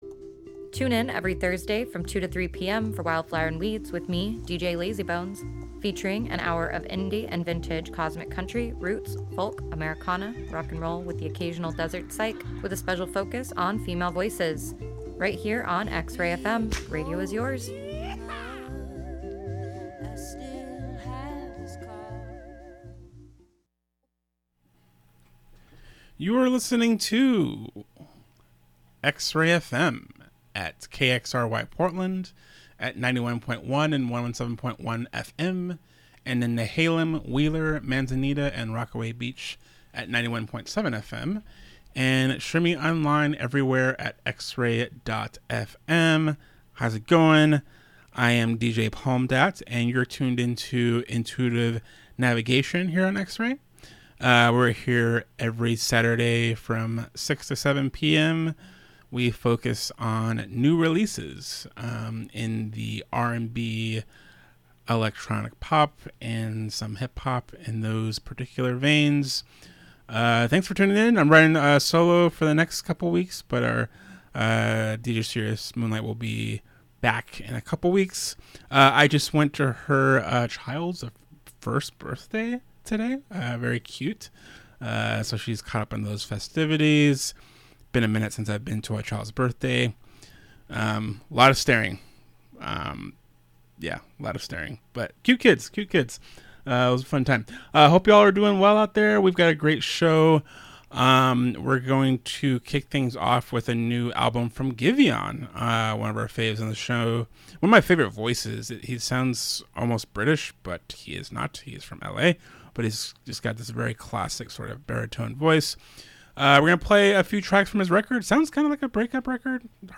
The latest in electronic pop, R&B, and other soulful music.
Feels-talk, moon-talk, and sultry jams.